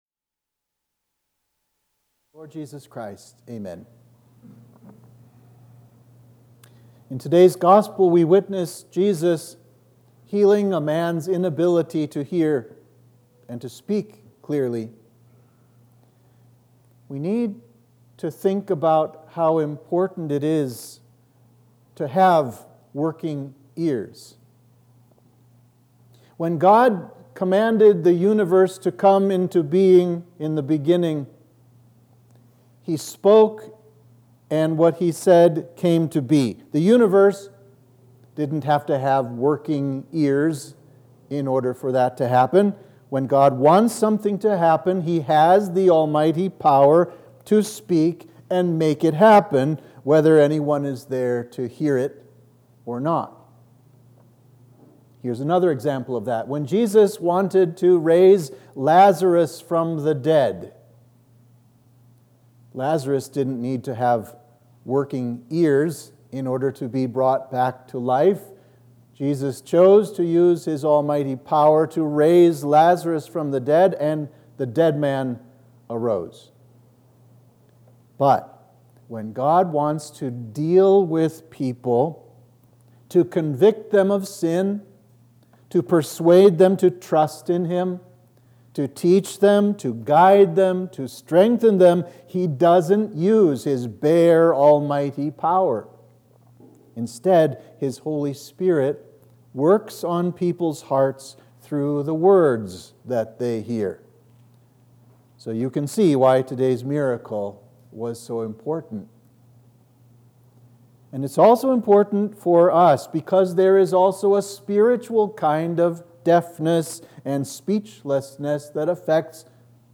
Sermon for Trinity 12